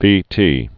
(vētē)